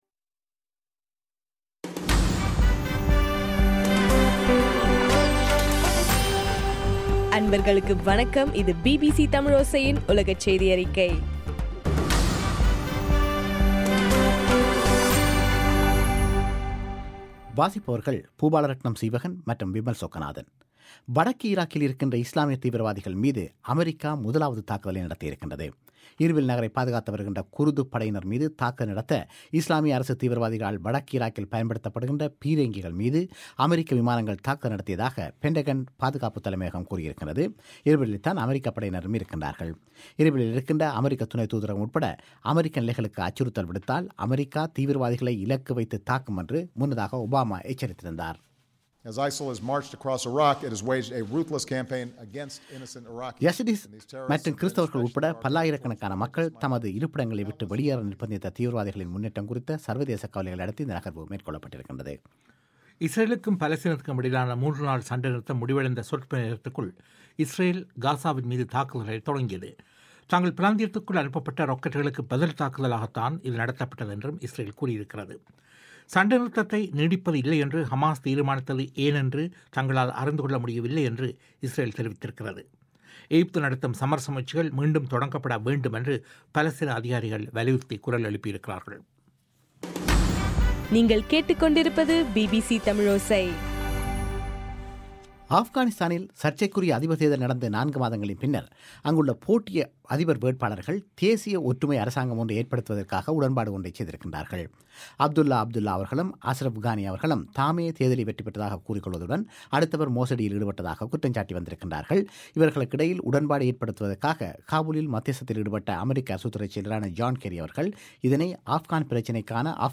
ஆகஸ்ட் 8, 2014 பிபிசி தமிழோசையின் உலகச்செய்திகள்